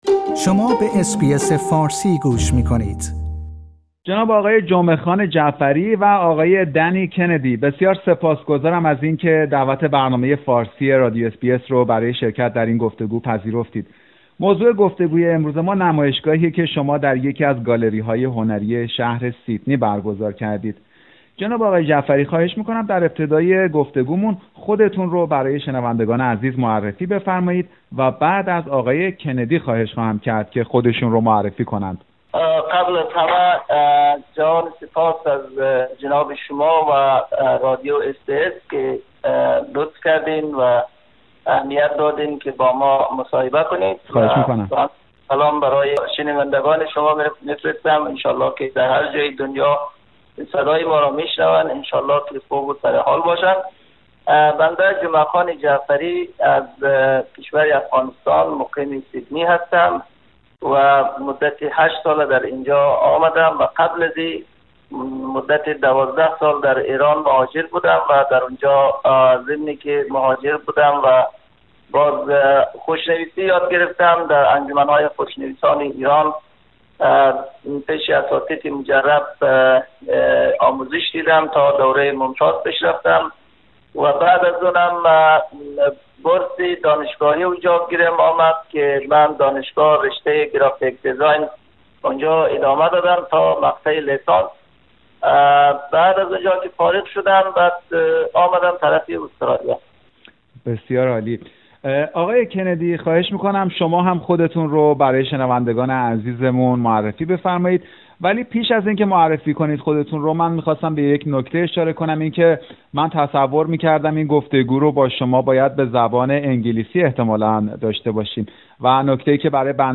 در همین خصوص، برنامه رادیو اس بی اس گفتگویی داشته با این دو هنرمند و جویای اهداف آنها از برگزاری این نمایشگاه شده است.